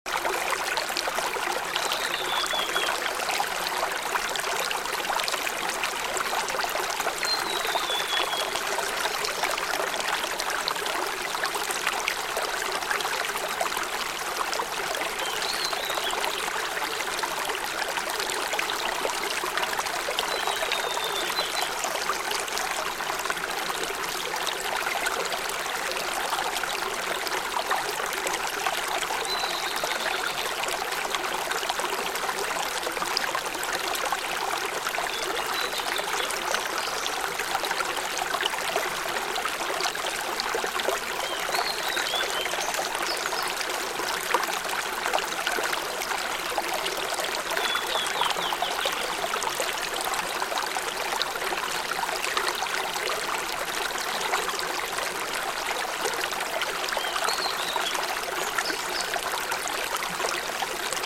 This is the perfect deep-sleep ASMR for those struggling with insomnia. Let the sound of the forest stream melt away the tension in your body and mind.